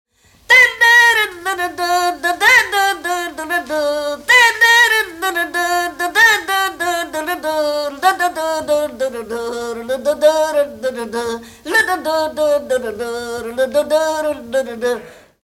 Kezdősor "Dudautánzás"
Műfaj Hangszeres
Részl.műfaj Hangszerutánzás
Helység Szany